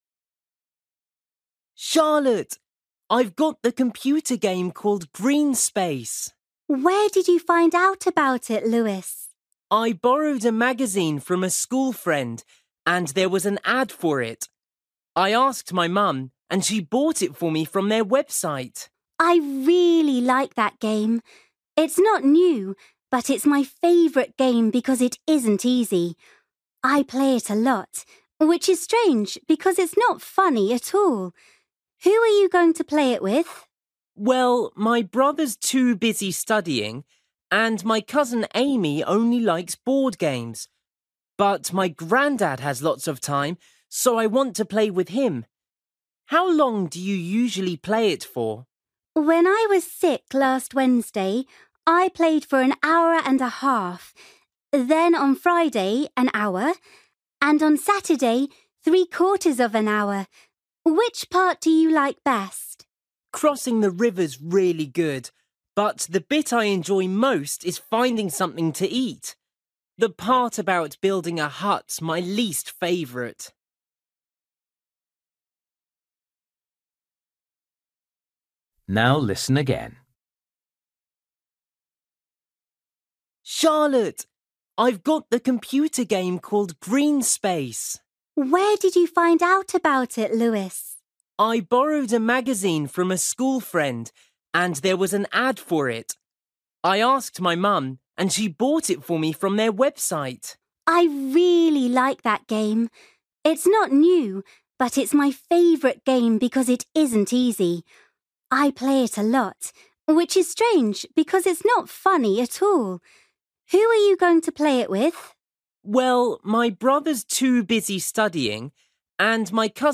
Bài tập trắc nghiệm luyện nghe tiếng Anh trình độ sơ trung cấp – Nghe một cuộc trò chuyện dài phần 1
You will hear Luis talking to his friend Charlotte about a computer game.